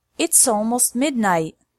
For native speakers, words that end in ‘s are connected to the next word:
• It’s almost midnight. (not connected)
• It’sssalmost midnight (connected – more natural)